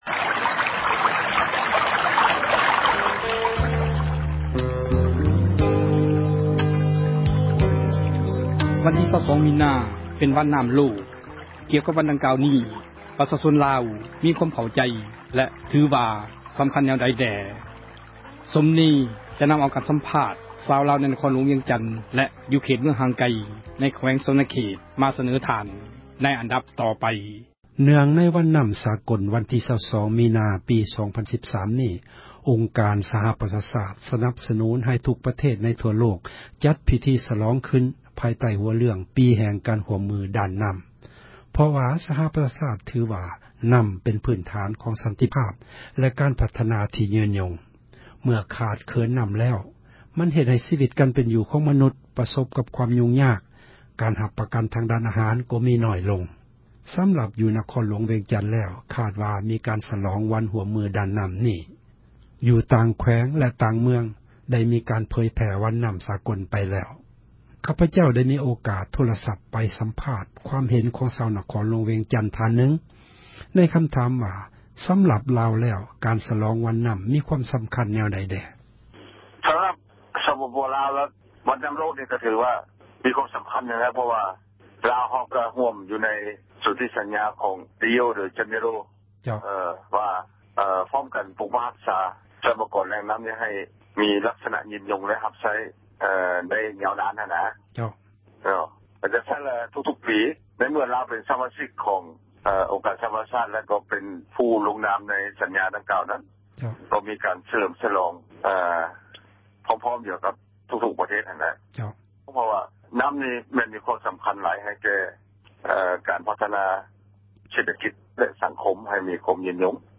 ການສຳພາດ ຊາວລາວ ໃນ ນະຄອນຫລວງ ວຽງຈັນ ແລະ ຢູ່ເຂດ ເມືອງຫ່າງໄກ ໃນແຂວງ ສວັນນະເຂດ